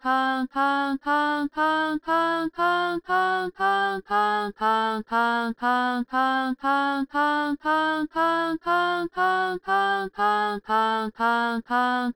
母音音声の無限音階化
endless_up_a.wav